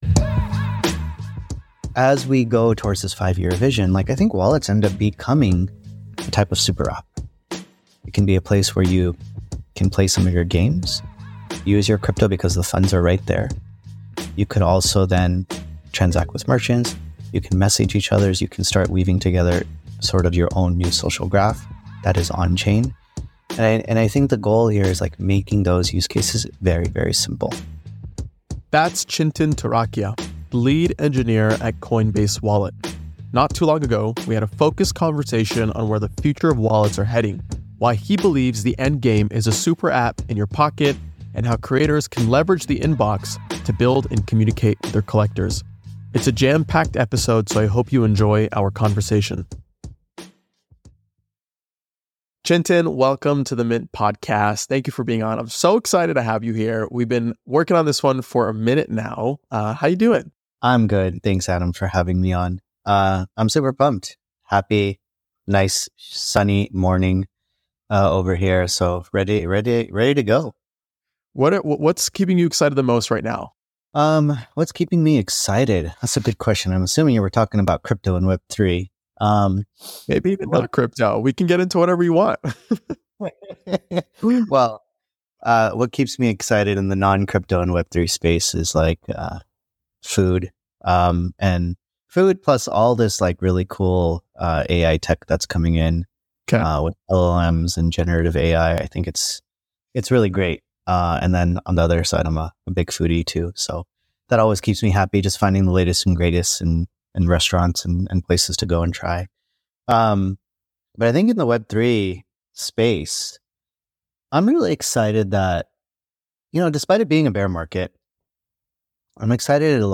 I hope you enjoy this jam-packed conversation.